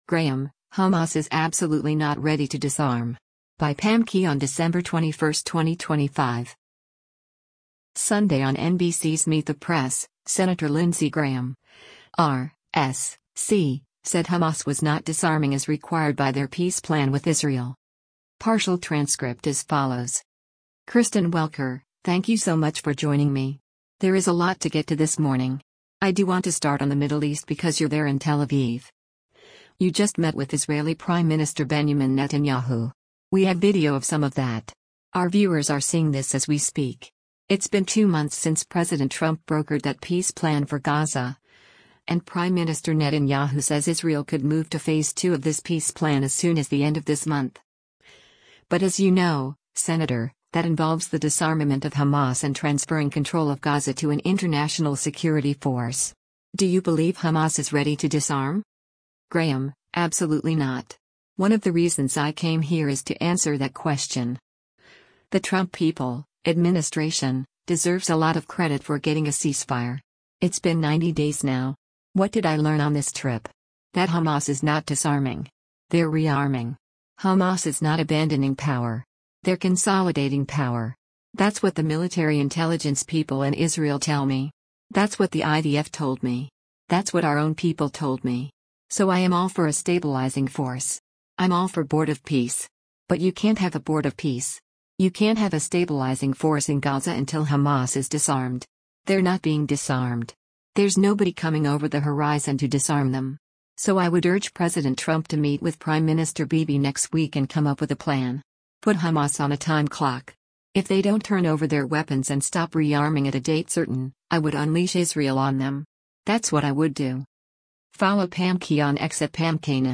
Sunday on NBC’s “Meet the Press,” Sen. Lindsey Graham (R-S.C.) said Hamas was “not disarming” as required by their peace plan with Israel.